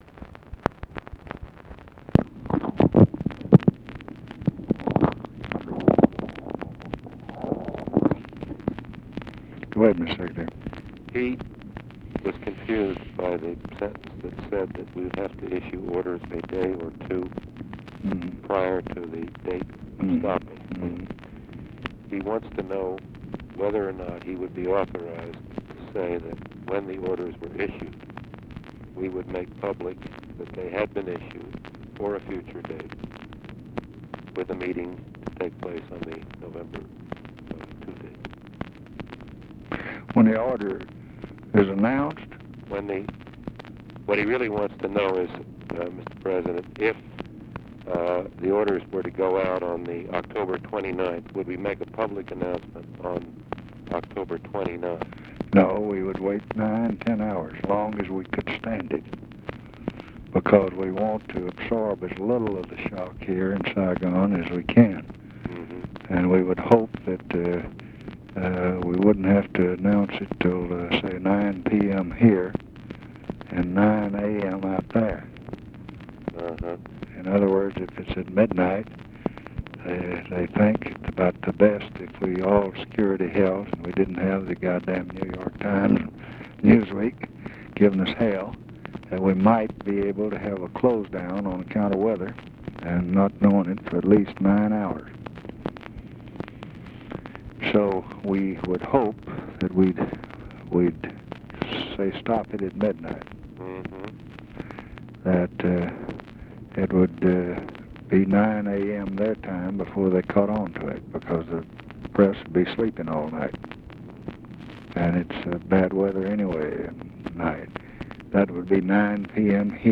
Conversation with NICHOLAS KATZENBACH, October 24, 1968
Secret White House Tapes